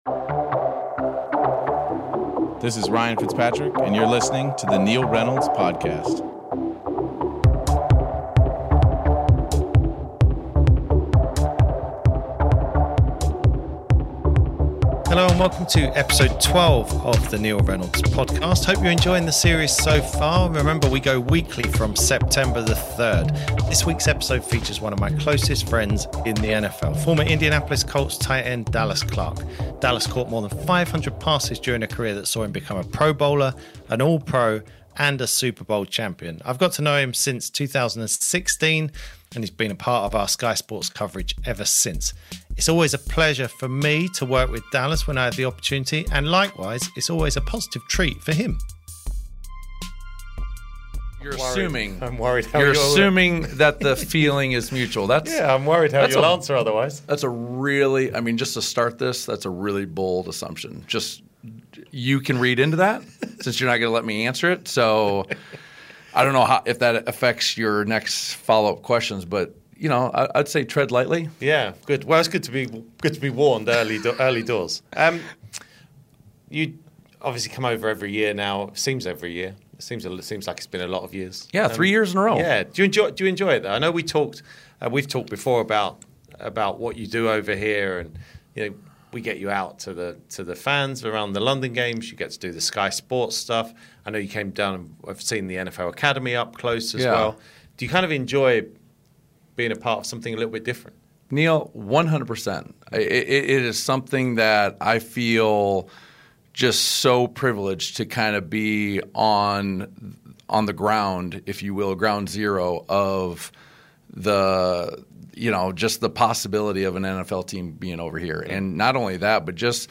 Have you ever heard an arm wrestle on a podcast? Better still, an arm wrestle on a podcast where one competitor is allowed to use both arms and still loses?